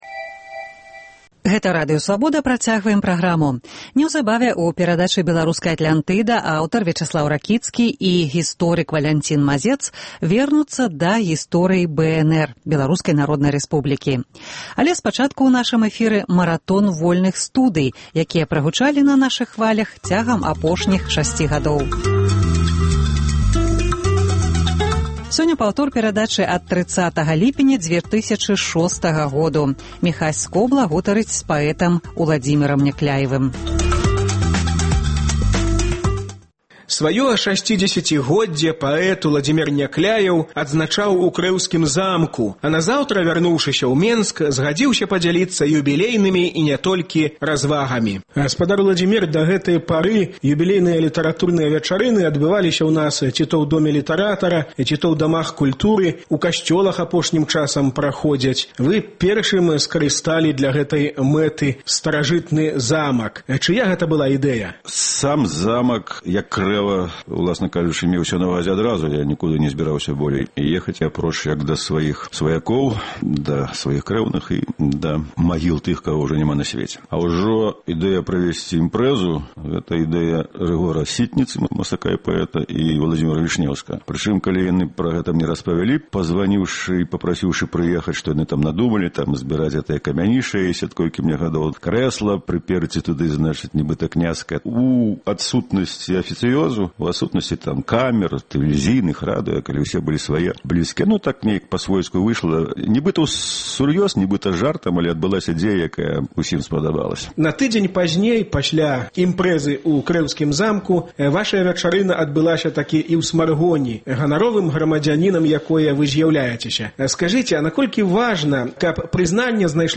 Мы працягваем маратон “Вольных студый”, якія прагучалі на нашых хвалях цягам апошніх сямі гадоў. Сёньня гутарка з паэтам Уладзімерам Някляевым.